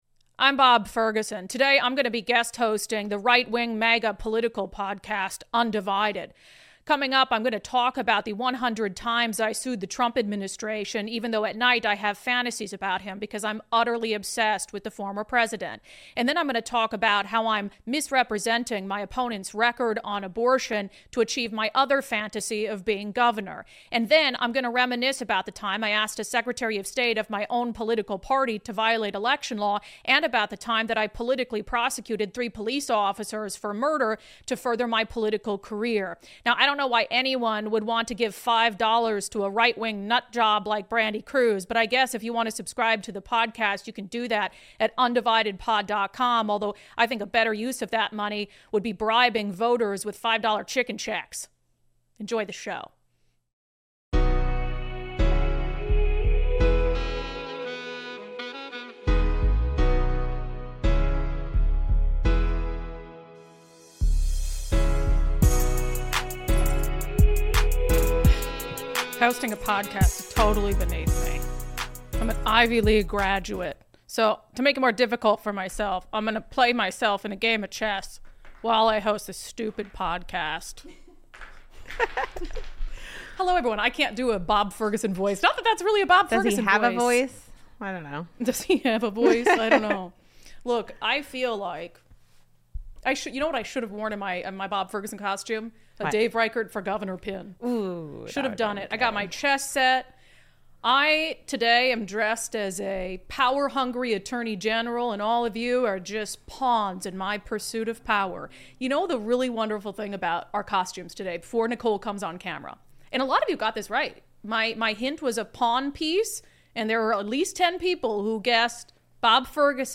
A special guest host joins unDivided. Democrats eager to paint Supreme Court candidate as an extremist.